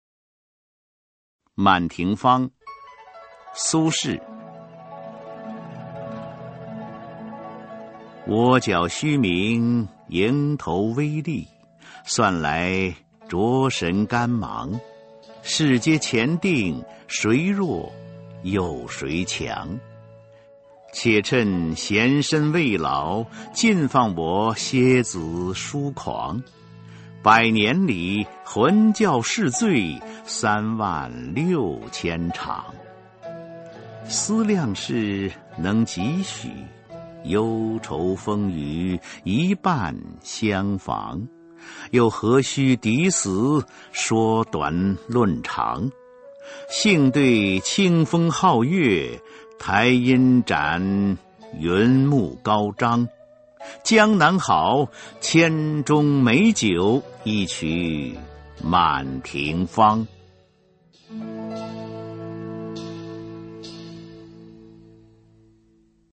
[宋代诗词诵读]苏轼-满庭芳·蜗角虚名 宋词朗诵